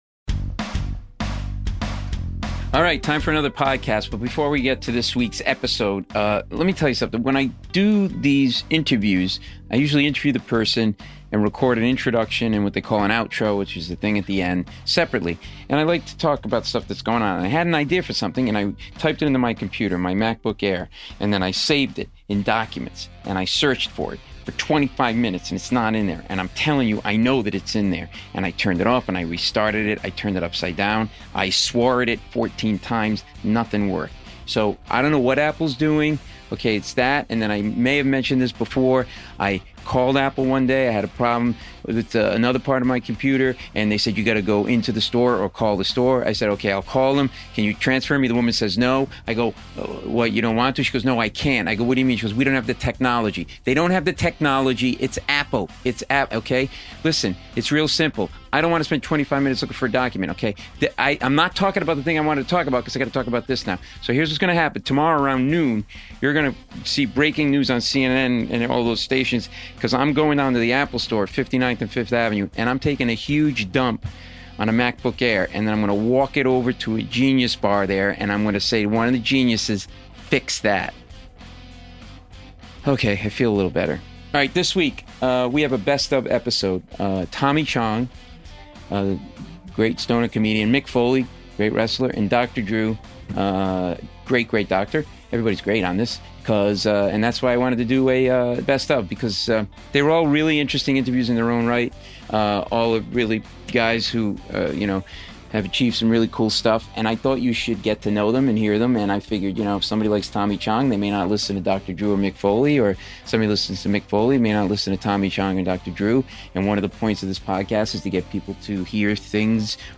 In case you missed these interviews. I loved talking with these 3 unique guys and was so excited about the interviews because all 3 were very cool, funny, and super interesting that I wanted you to hear some of my conversations with them.